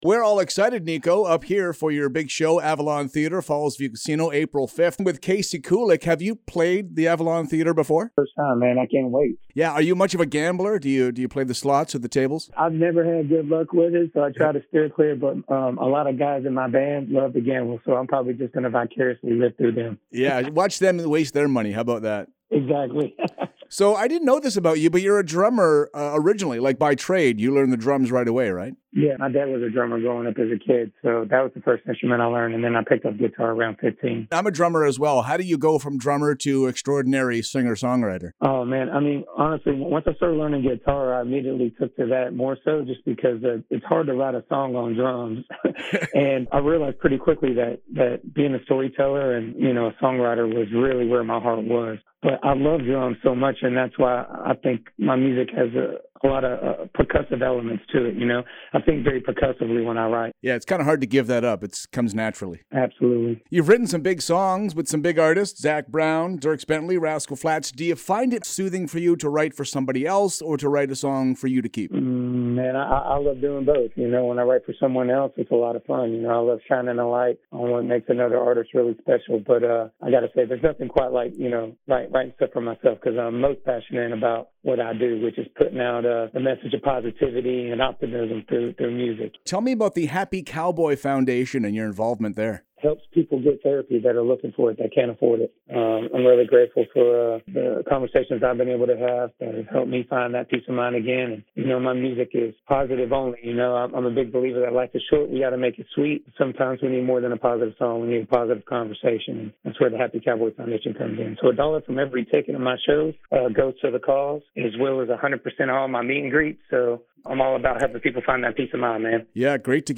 Tune in every Friday morning for weekly interviews, performances, everything LIVE!
niko-moon-interview-april-5th-avalon.mp3